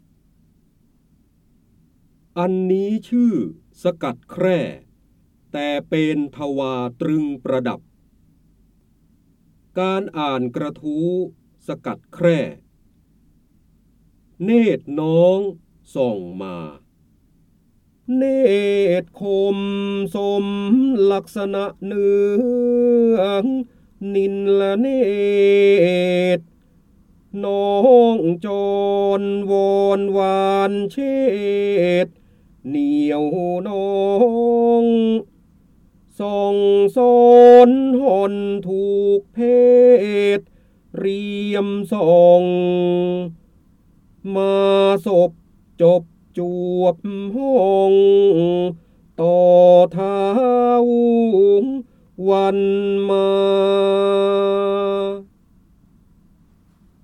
เสียงบรรยายจากหนังสือ จินดามณี (พระโหราธิบดี) อันนี้ชื่อสกัดแคร่ แต่เปนทวาตรึงประดับ
คำสำคัญ : จินดามณี, ร้อยกรอง, พระโหราธิบดี, พระเจ้าบรมโกศ, การอ่านออกเสียง, ร้อยแก้ว